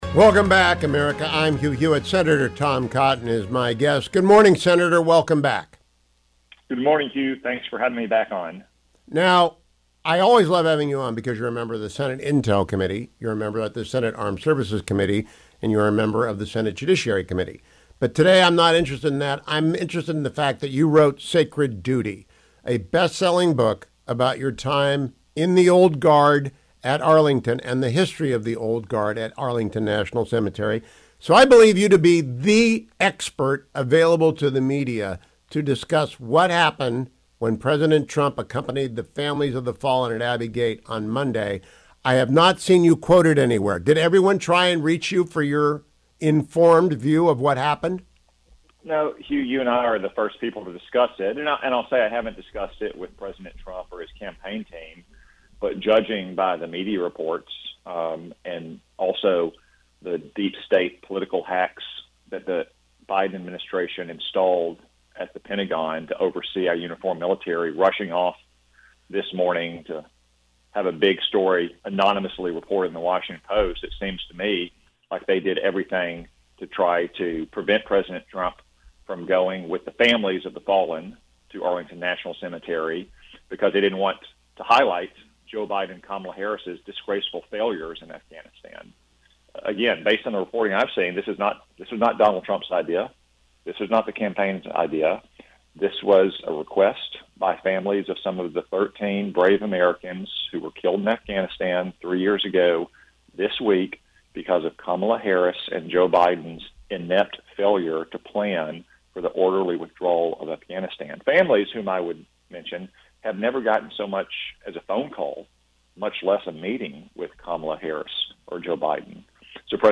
Senator Tom Cotton joined me this morning and of course I asked him about the absurd “controversy” over former President Trump’s visiting Arlington National Cemetery on Monday at the invitation of the Gold Star families of the fallen at Abbey Gate.